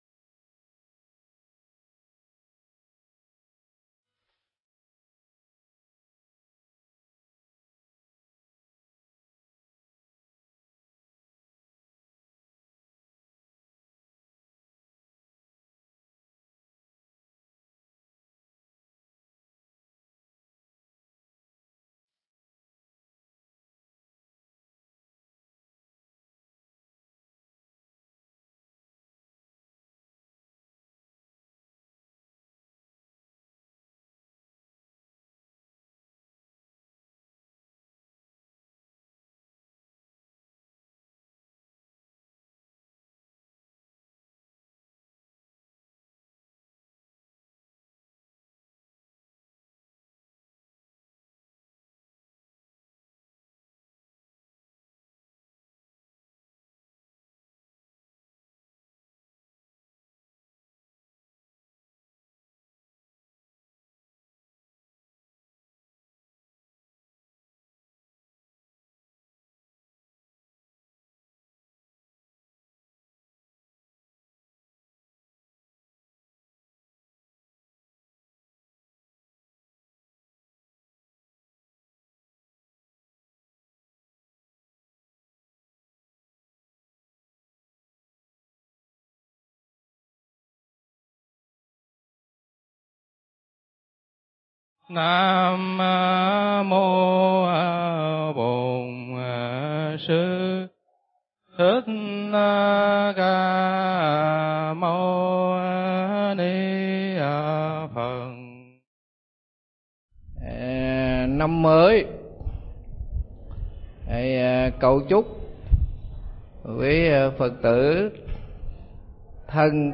Nghe Mp3 thuyết pháp Sự Mầu Nhiệm Của Phương Pháp Sám Hối